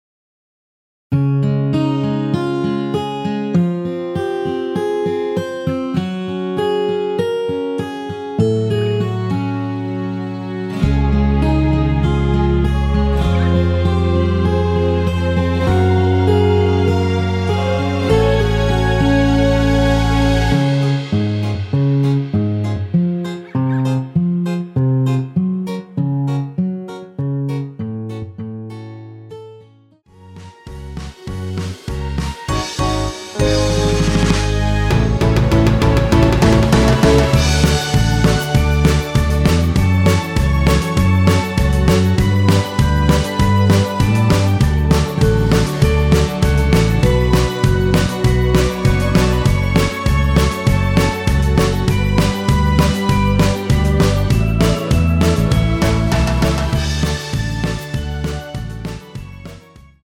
설레임과 달콤한 가사가 눈에 띄는 2013년 러브송
Db
◈ 곡명 옆 (-1)은 반음 내림, (+1)은 반음 올림 입니다.
앞부분30초, 뒷부분30초씩 편집해서 올려 드리고 있습니다.